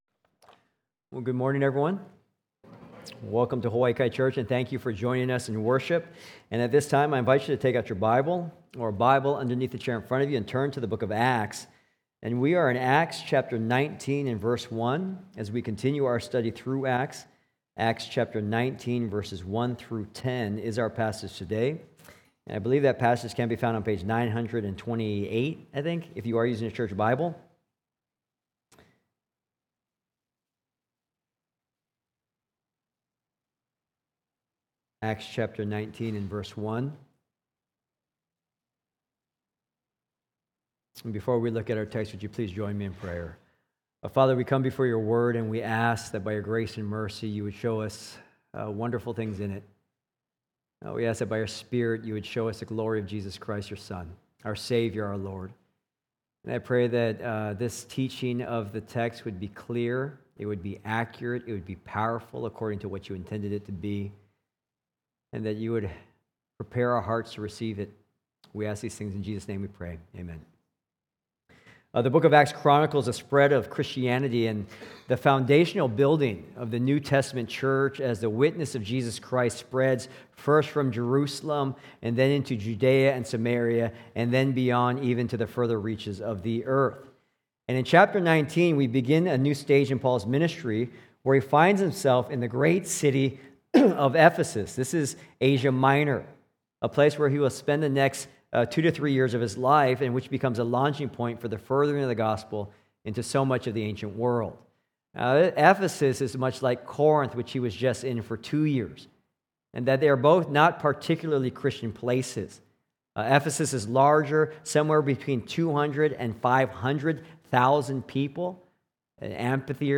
Sermons
Sermons from Hawaii Kai Church: Honolulu, HI